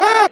the-rock-aahh.mp3